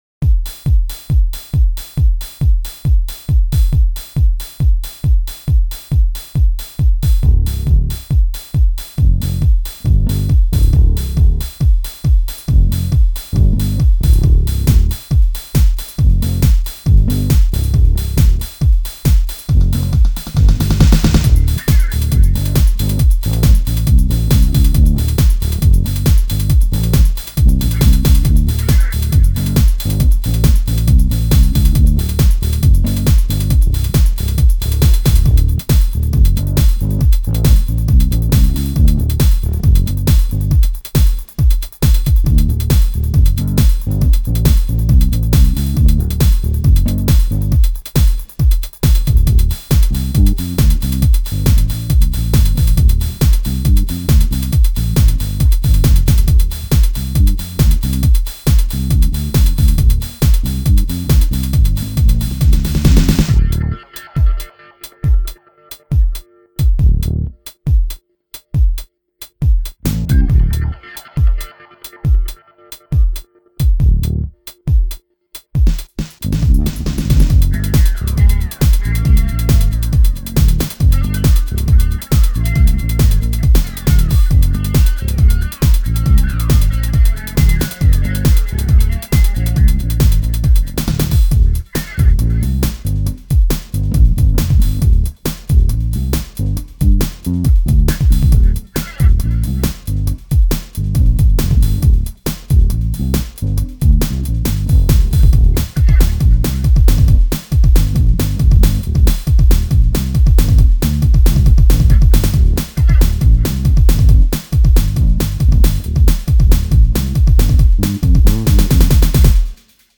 So those drums, with a little filtering, are what make up the core of the dance beats for this song.
Though, to flesh out the sound a bit, I added some effects, some eq, and actually doubled the beats on one of the default Live impact drumsets at a lower level. Record some bass and guitar, add some effects to flavor (aside from guitar rig, everything has default Live 7 effects) and go.